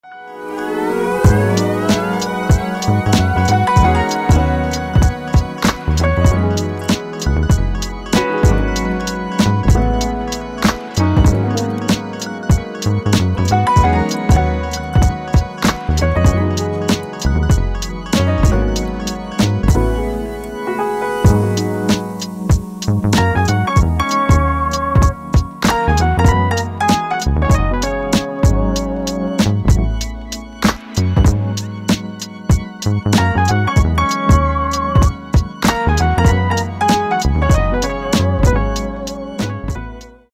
Рингтоны без слов
Chillout , Lounge , Lo-fi , Инструментальные